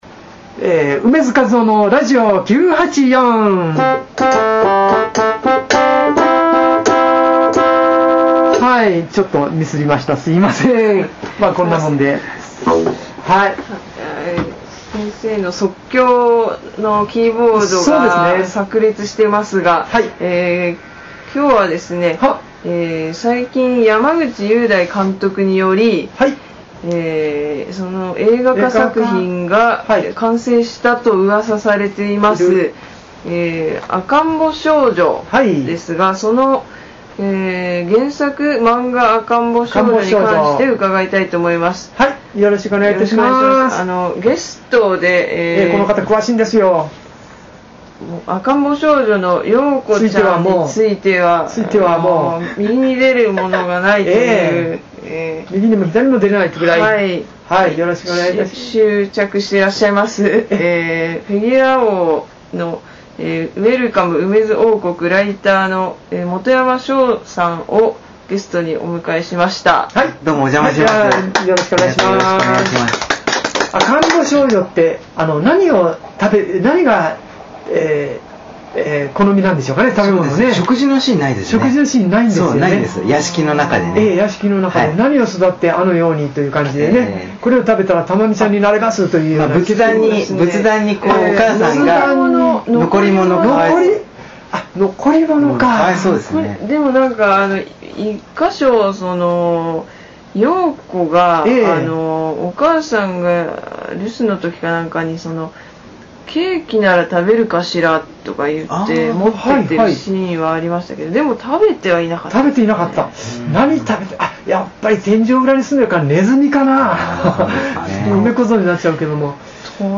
映画『赤んぼ少女』(山口雄大監督 公開時期未定)の完成を記念し、漫画『赤んぼ少女』について、楳図かずおが語ります！！